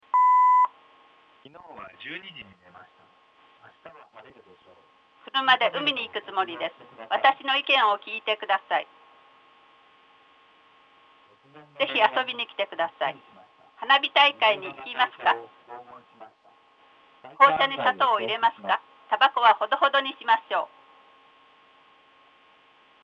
• EC(Echo canceller)が筐体マイクの集音するエコーを低減します。
PCステレオ信号出力のLch男声、Rch女声の組み合わせです。
Patt01_L男性R女性_ECON_SCON.mp3